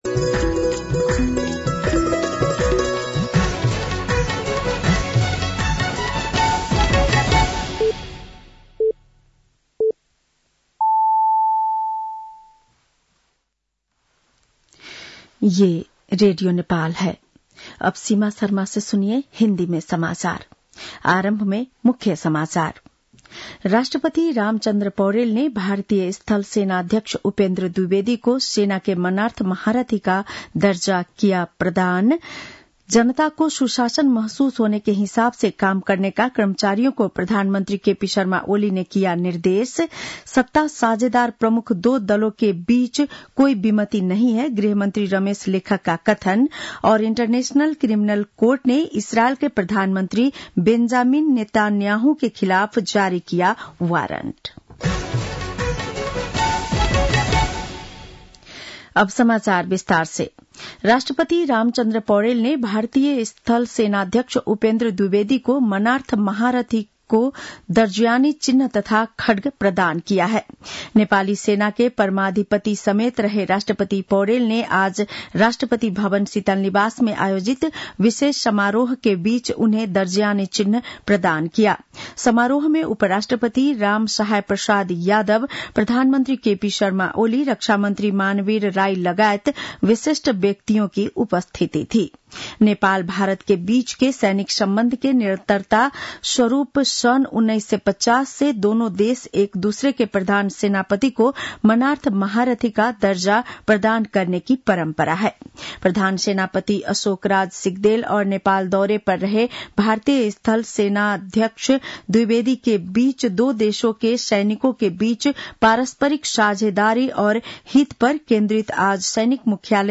बेलुकी १० बजेको हिन्दी समाचार : ७ मंसिर , २०८१
10-PM-Hindi-NEWS-8-6.mp3